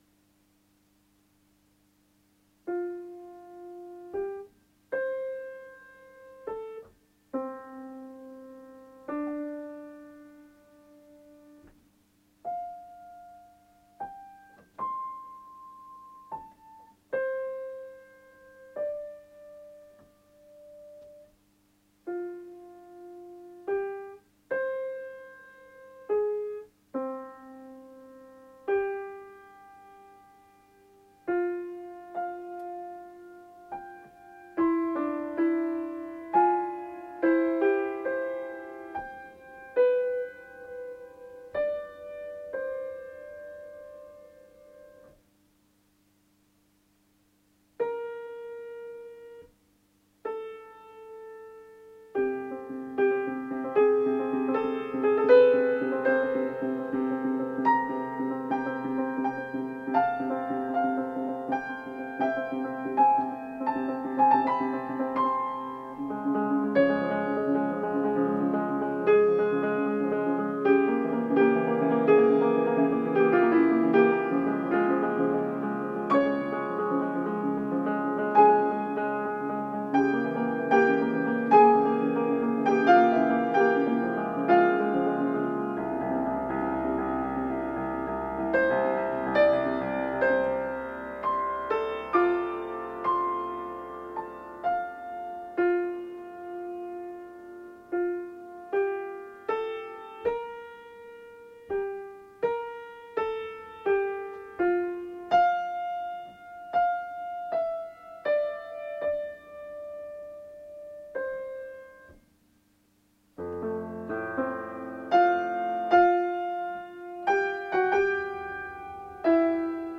序奏(1-19)F dur
＜＜＜確認のためだけの下手なmp3＞＞＞
2人の羊飼いによるラン・デ・ヴァッシュ(牛追い歌)の応答は、劇音楽的手法が用いられ、コールアングレーの呼びかけに対して、応答するオーボエはなんと舞台裏から演奏するように指示が書かれている。
・フルートとヴァイオリンで主人公が彼女を想う主題が極めて薄い和声付けで、ほとんど単旋律のように提示される。